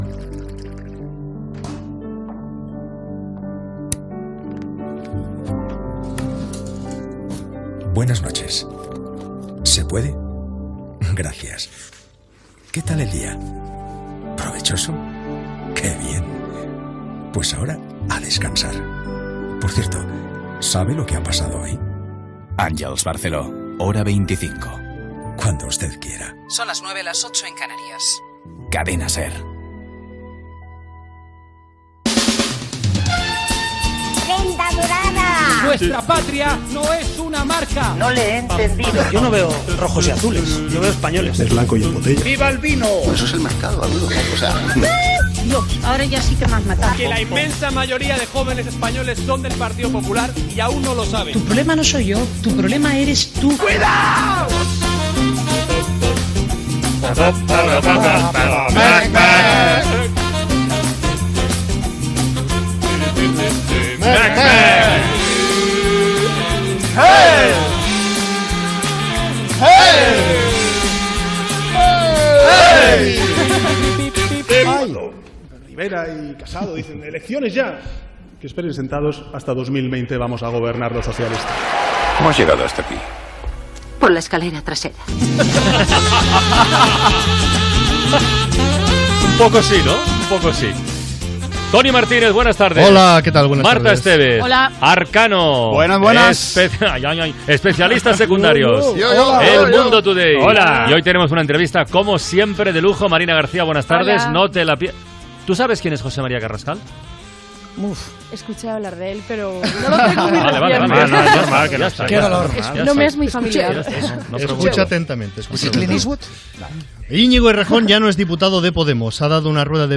Promoció "Hora 25", espai "Todo por la radio": el polític Íñigo Herrejón, el ministre José Luis Ábalos, la cançó espanyola d'Eurovisió, rap sobre eurovisió
Entreteniment